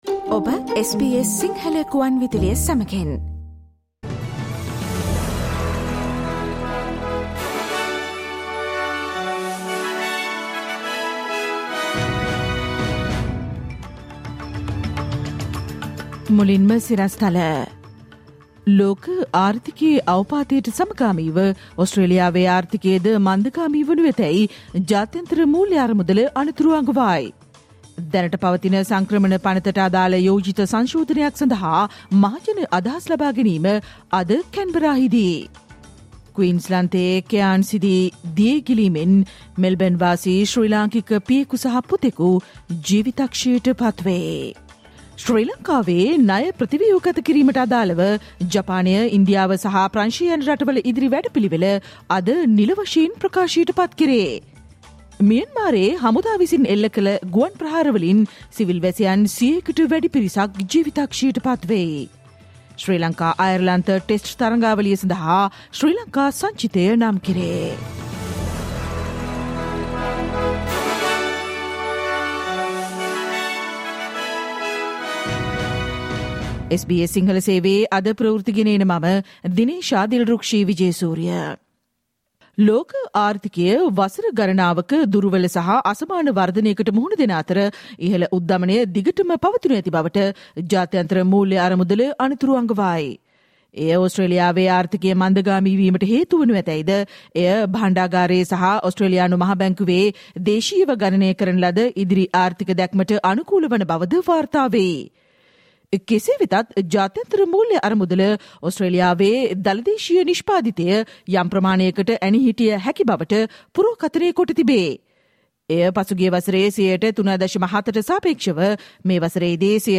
Listen to the SBS Sinhala Radio news bulletin on Thursday 13 April 2022